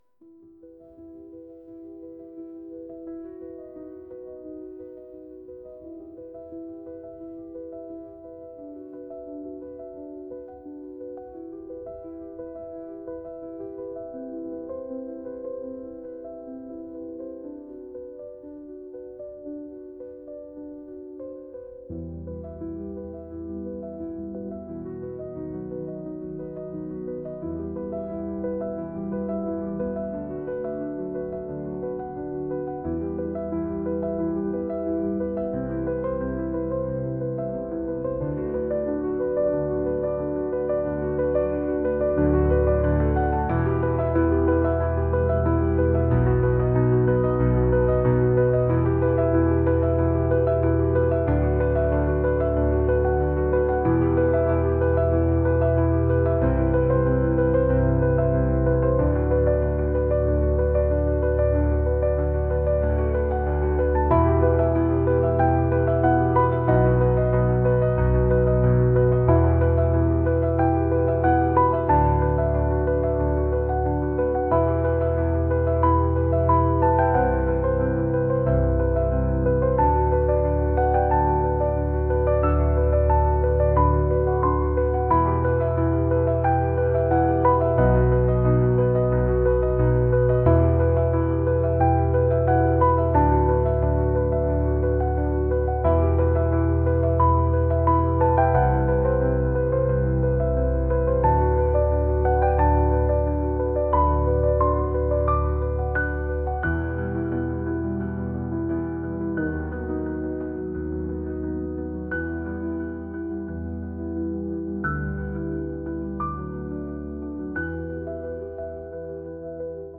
classical | ambient | cinematic